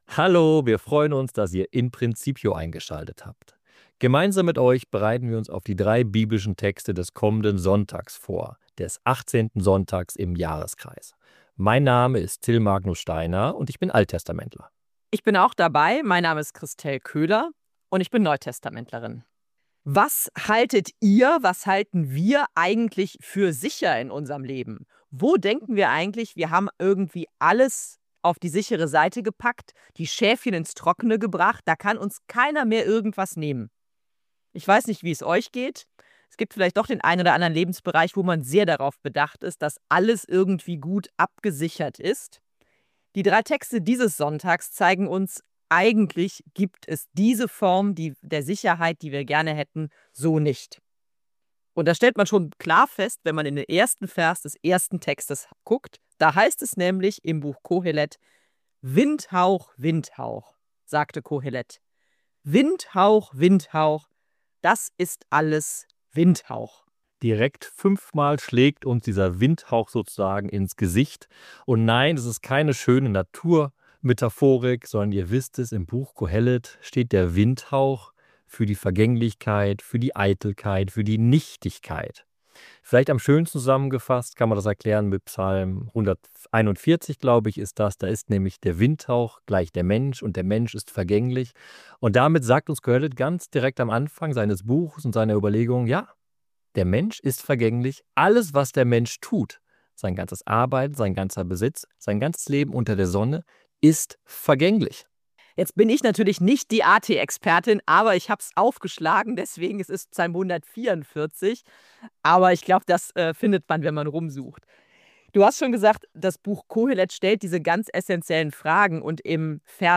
während sie im Gespräch die biblischen Texte